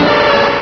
pokeemerald / sound / direct_sound_samples / cries / slowbro.aif